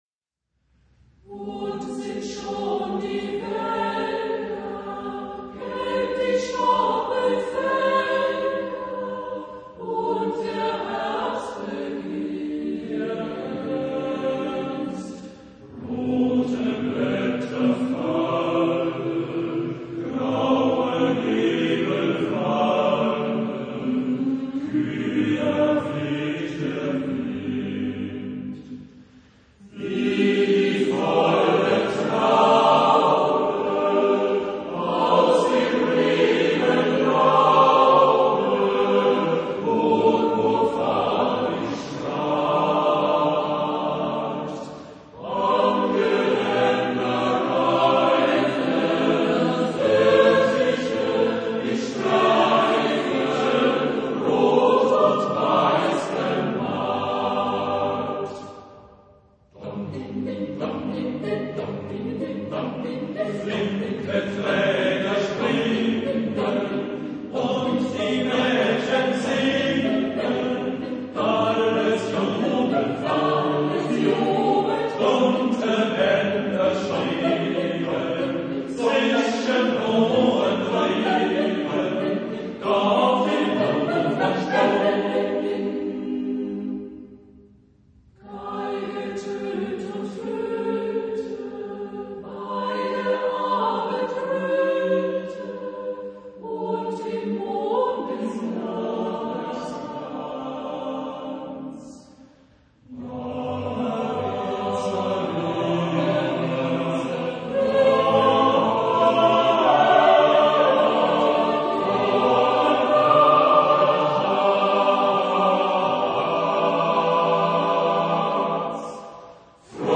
Genre-Style-Forme : Chanson ; Folklore ; Profane
Type de choeur : SAATBB  (6 voix mixtes )
Tonalité : sol majeur